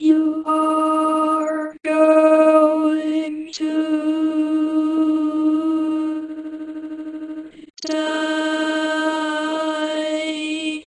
Dice Rolling Sound WOOD SURFACE
描述：The sound of a dye being rolled, I found that it doesn't matter whether it's a 6 sided dye, a 4 sided dye, a 20 sided dye, or a 60 sided dye, it all sounds the same... and yes I have a 60 sided dye...
标签： BoardGame Dice Playing Dye
声道立体声